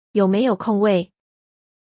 Yǒu méiyǒu kōngwèi?